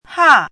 chinese-voice - 汉字语音库
ha4.mp3